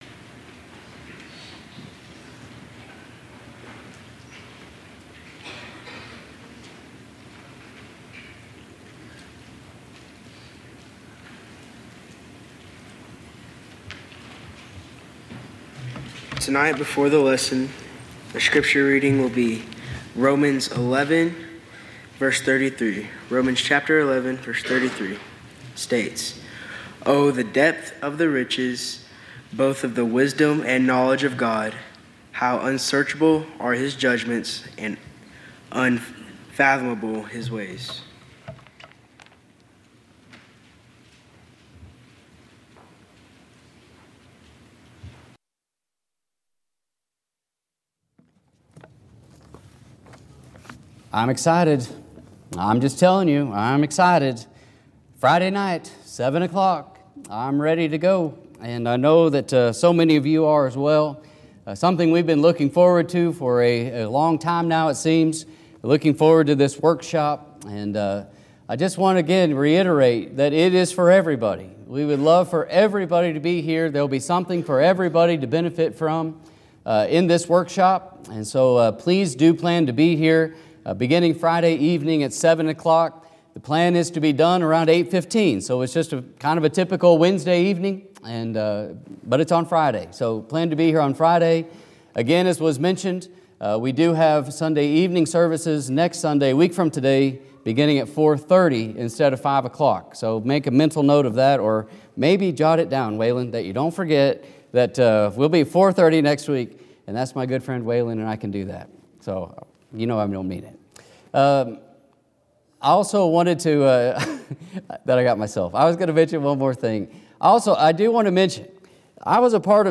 Sermons by Westside Church of Christ